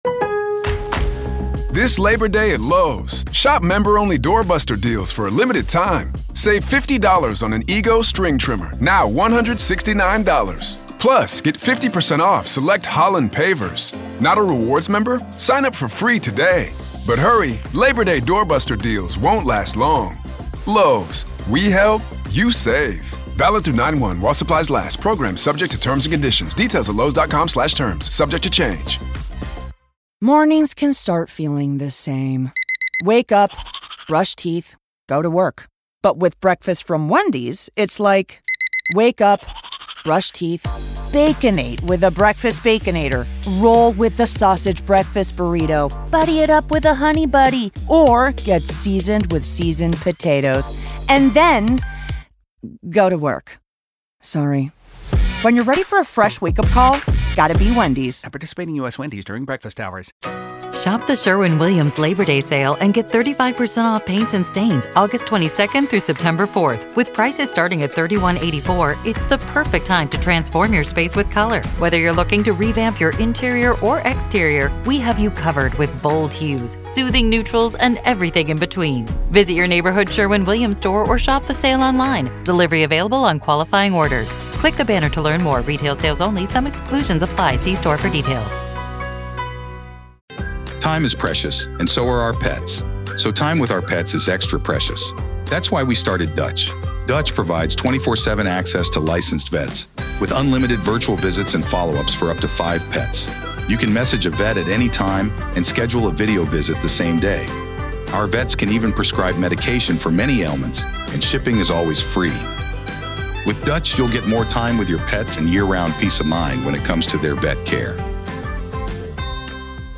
LISTEN: The Trial Of Lori Vallow Daybell Day 4 Part 3 | Raw Courtroom Audio